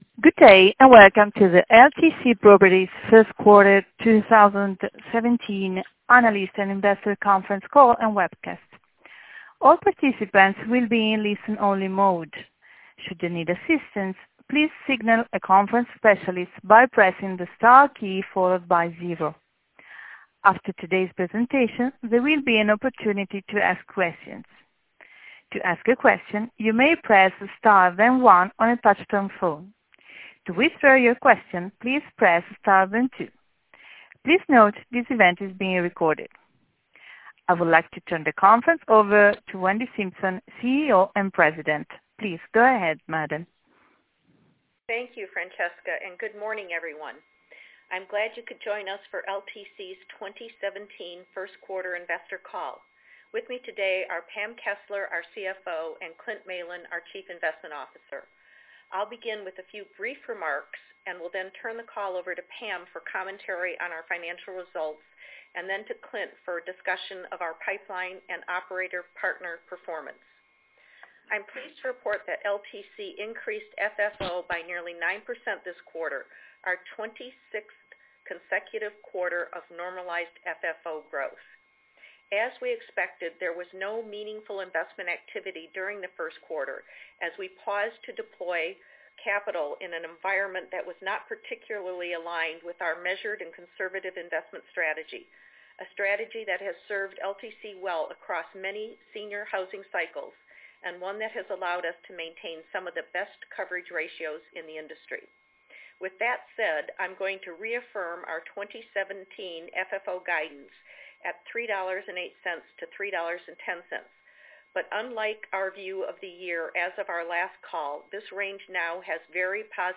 Earnings Webcast Q1 2017 Audio
Audio-Replay-of-LTC-Properties-Inc-Q1-2017-Earnings-Call.mp3